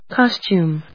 音節cos・tume 発音記号・読み方
/kάst(j)uːm(米国英語), kˈɔstjuːm(英国英語)/